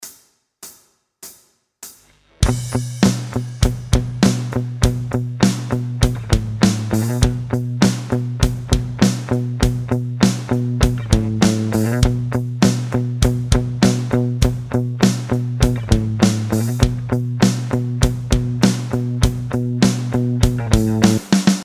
B Minor Backing Track